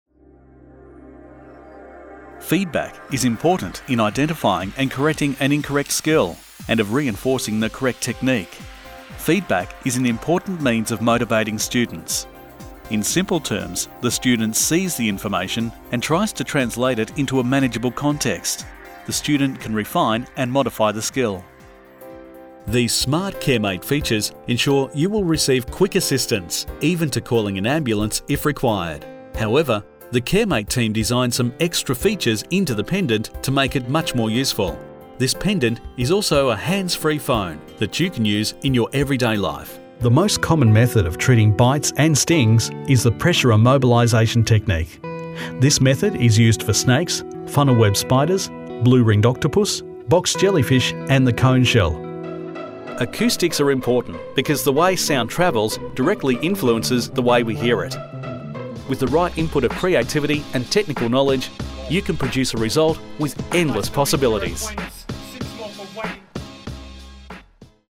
E=learning, Corporate & Industrial Voice Overs
Adult (30-50) | Older Sound (50+)